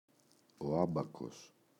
άμπακος, ο [Ꞌambakos] – ΔΠΗ